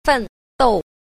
1. 奮鬥 – fèndòu – phấn đấu